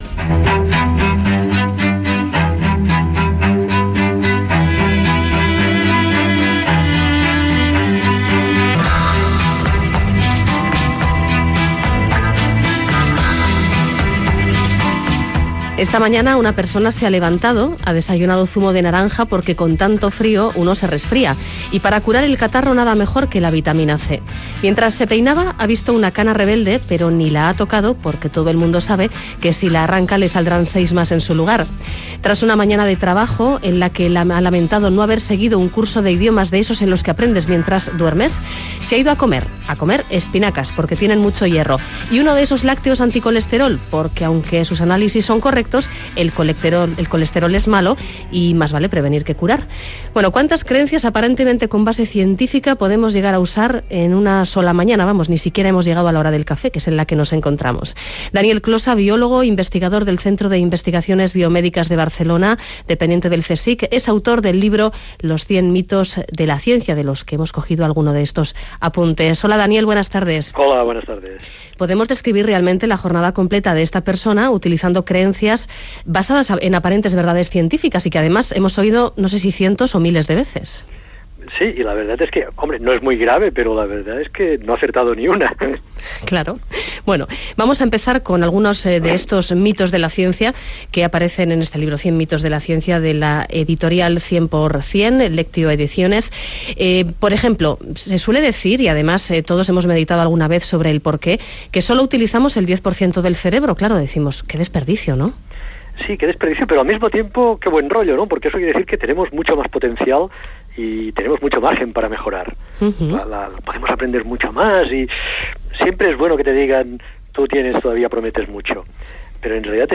A lo largo de estas dos entrevistas repasamos lo mejor de algunas de estas 100 leyendas urbanas de la ciencia.